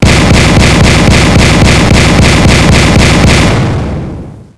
13shots
13shots.wav